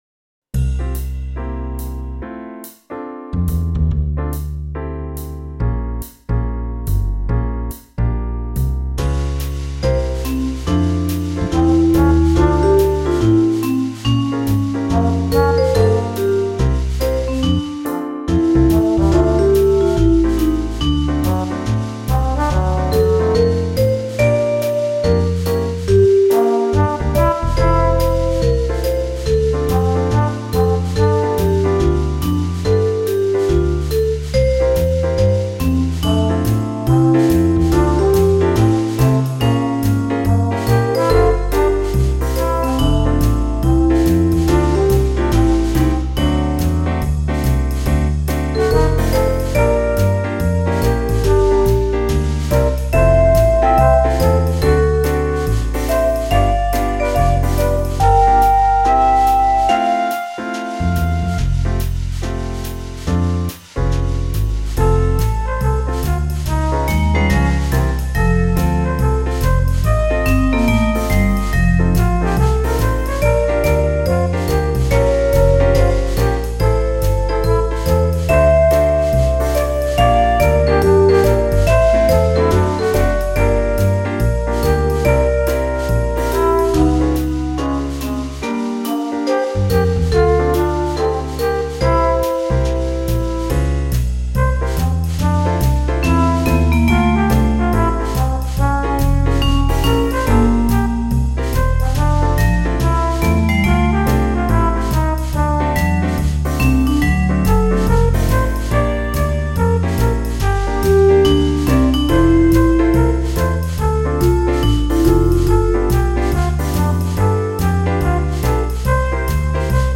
ein Swing für Susi